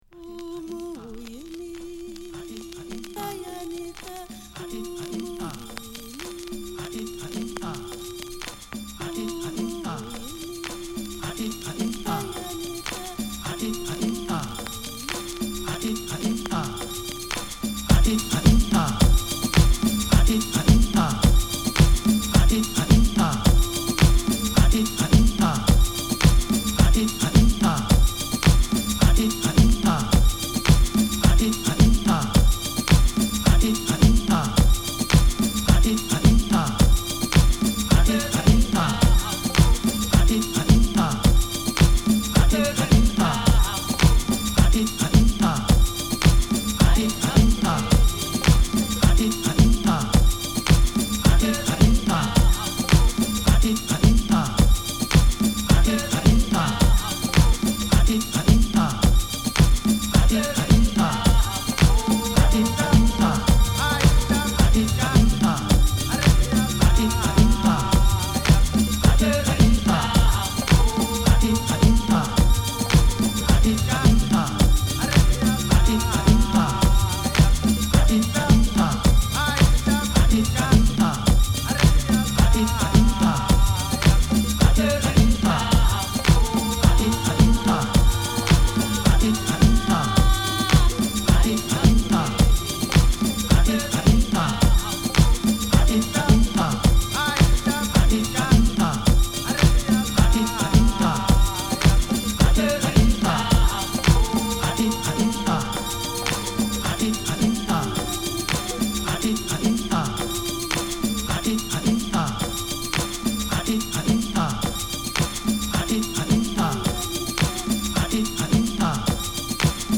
アフリカンコーラスが印象的なミッド・テンポのA1